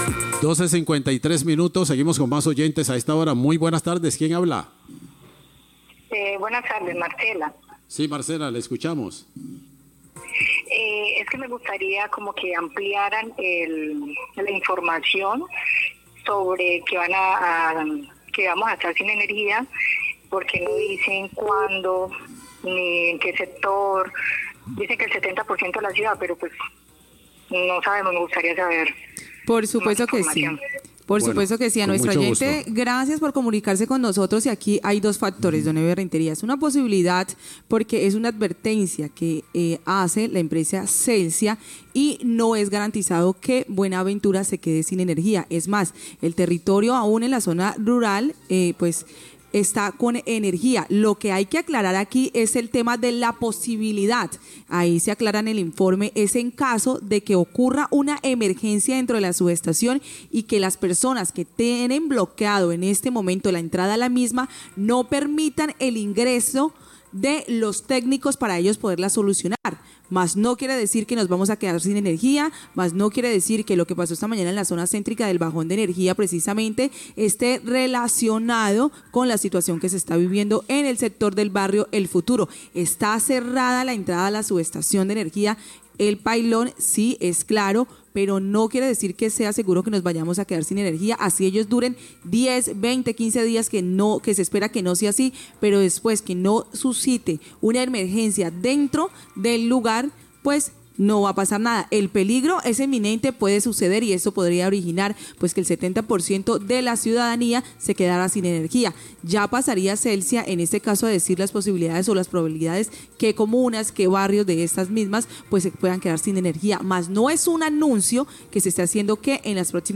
Radio
Después de la solicitud realizada por un oyente para claridad sobre la noticia del posible corte de energía al 70% de Buenaventura debido al bloqueo realizado por la comunidad del barrio El Futuro en la subestación Pailón, locutores realizan ampliación de la información sobre la situación.